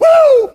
TM88 WoohVox.wav